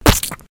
splat.ogg